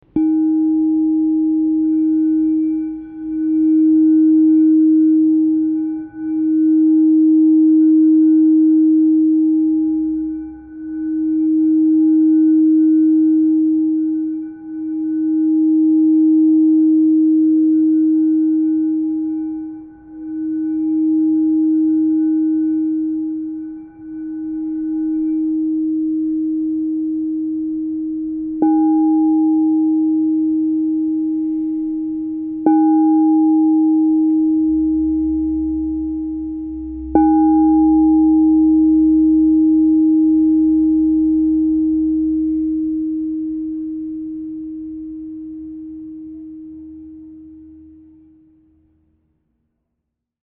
RÉ#4